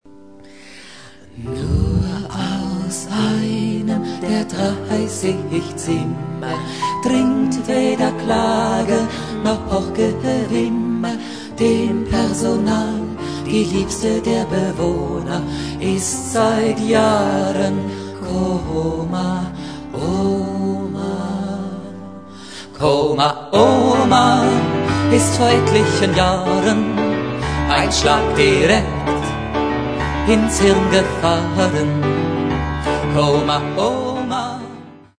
(Chanson, Kabarett)